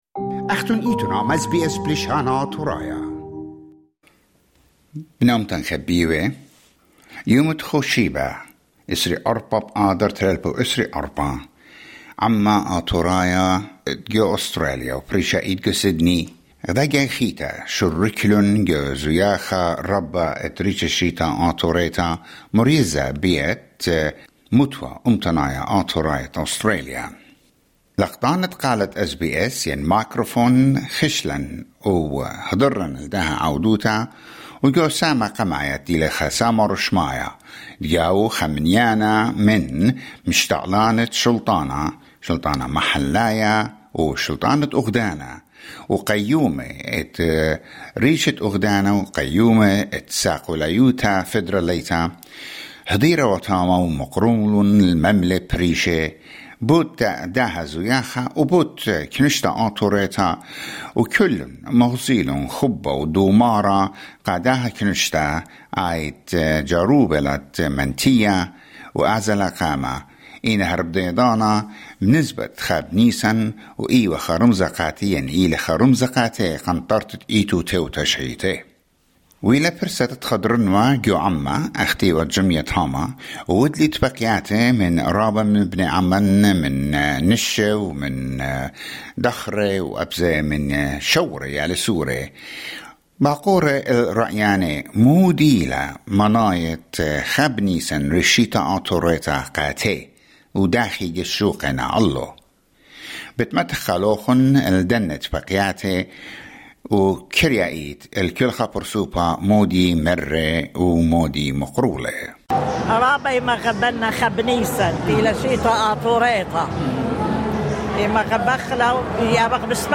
Over 8,000 Assyrians gathered for the Assyrian 6774 New Year Festival, which started at 9 a.m. and concluded with dazzling fireworks at the Fairfield Showground at 10 pm. Highlights and attendee interviews capture the essence of the vibrant celebration.